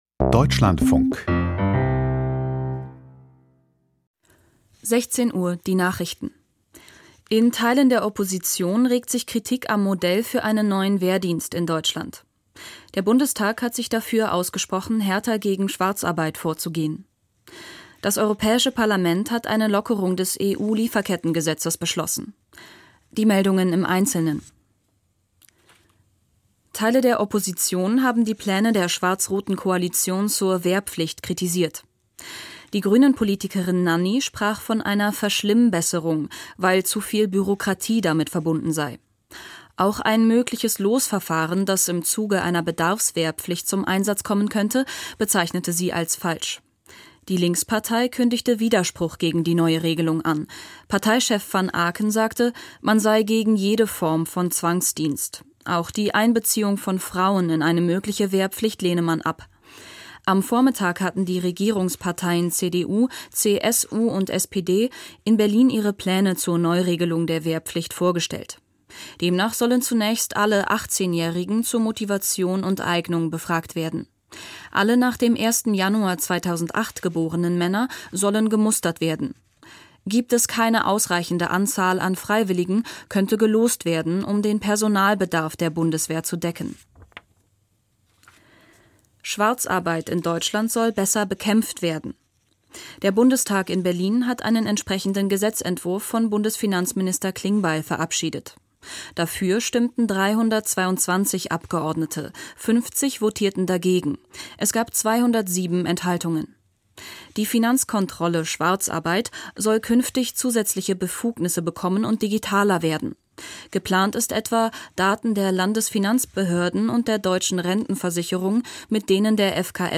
Die Nachrichten Die Nachrichten vom 13.11.2025, 16:00 Uhr Play episode November 13 9 mins Bookmarks View Transcript Episode Description Die wichtigsten Nachrichten aus Deutschland und der Welt.